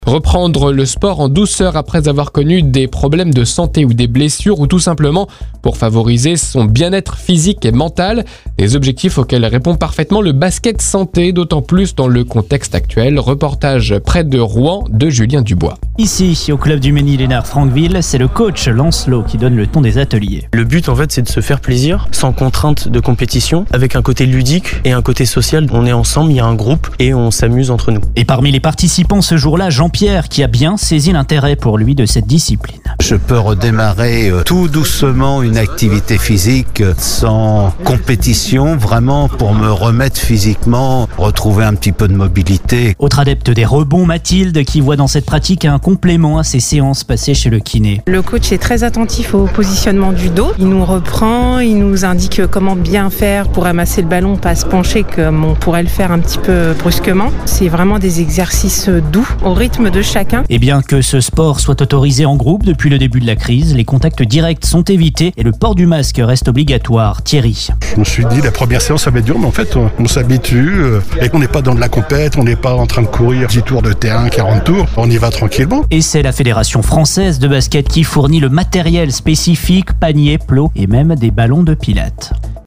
Pour preuve, un journalise est venu interviewer notre responsable de cette activité ainsi que quelques participants.